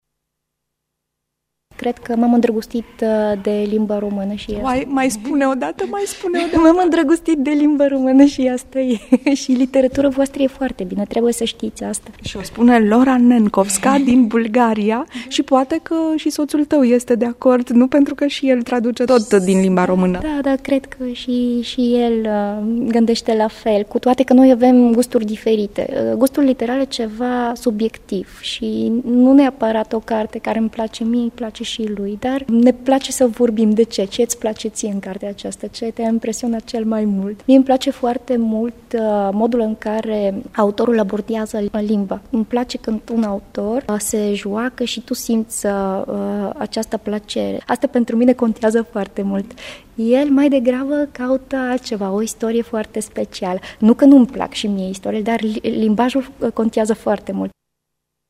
Ce mai spune ea, în cadrul interviului prilejuit de Turnirul Scriitorilor e o adevărată desfătare, o bucurie de nedescris.